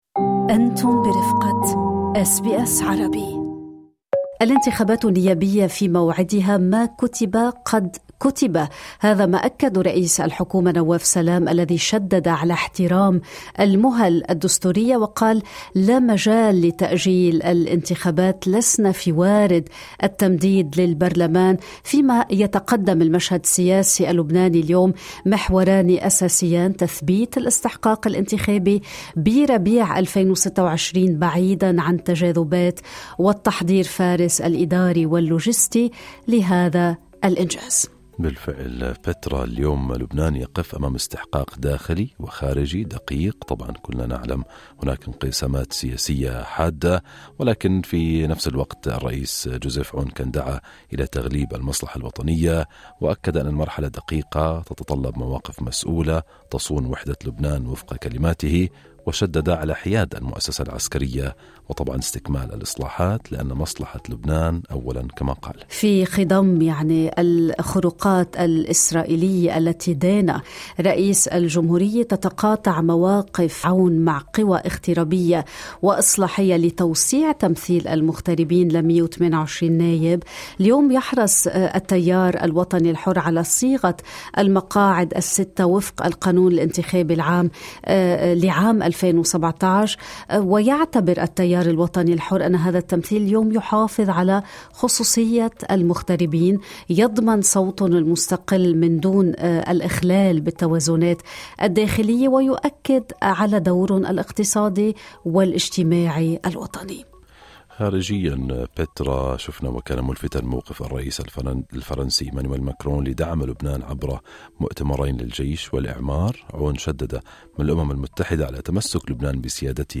Dr. Pierre Raffoul, former Lebanese Minister for Presidential Affairs and Political Council Member of the reformist FPM Party speaks to “Good Morning Australia” as he visits Sydney